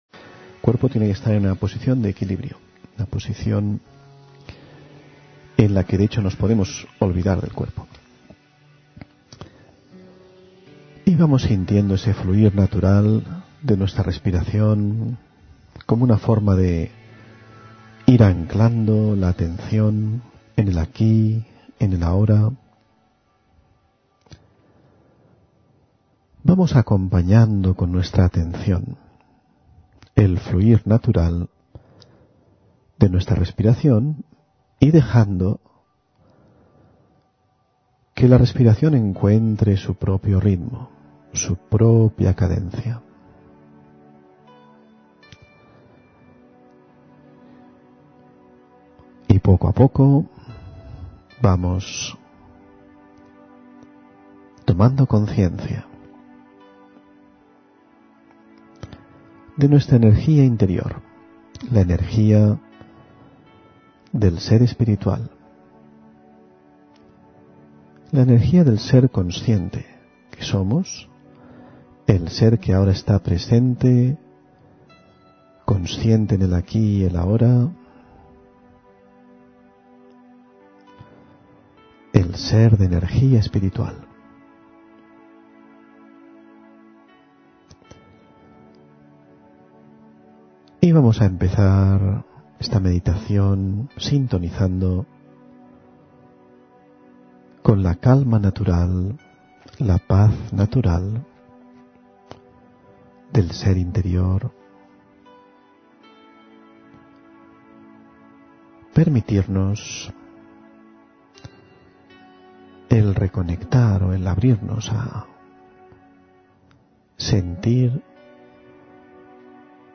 Meditación de la mañana: Conversación interior (10 Agosto 2020)